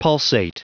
Prononciation du mot pulsate en anglais (fichier audio)
Prononciation du mot : pulsate